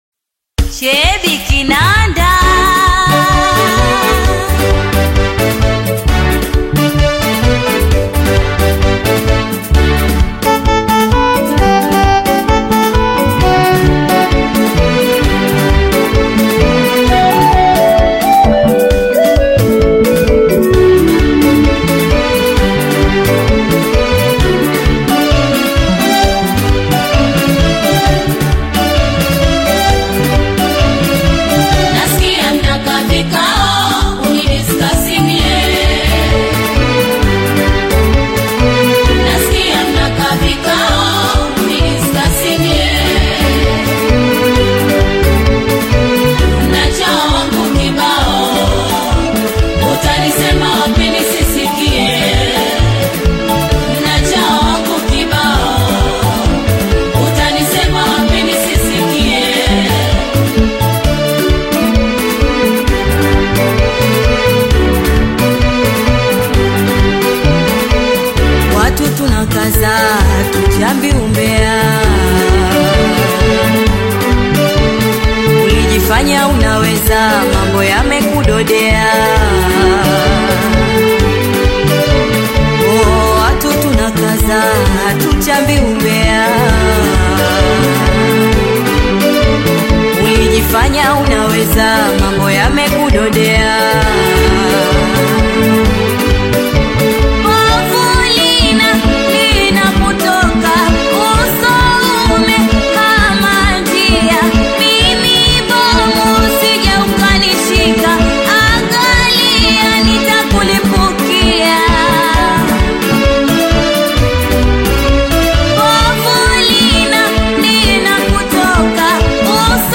has just unveiled her captivating new taarab song